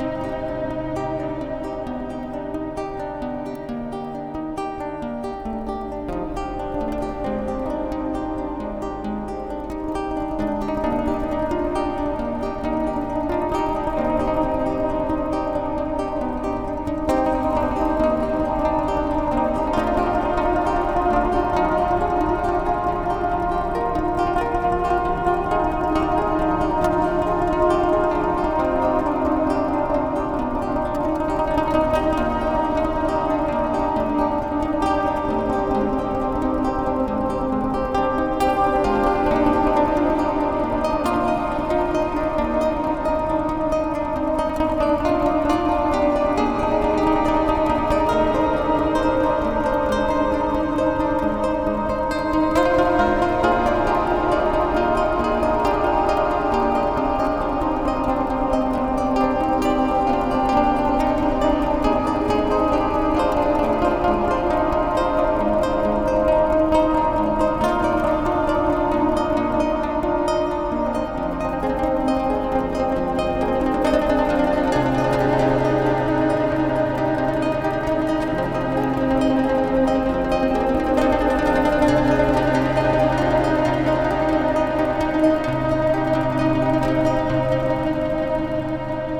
014-loop.wav